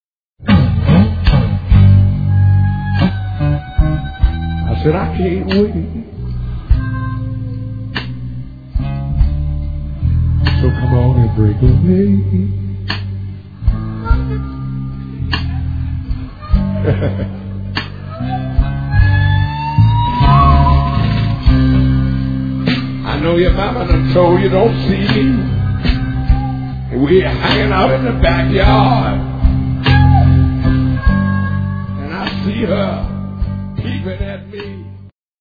BLUES
Me (saw). Live from Brno [2002].